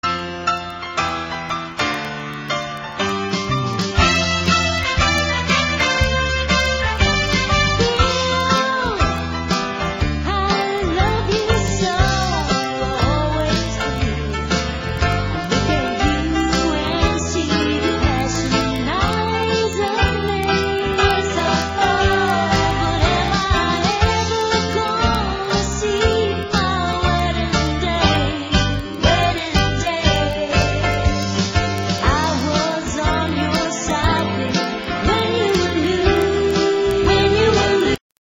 NOTE: Vocal Tracks 1 Thru 6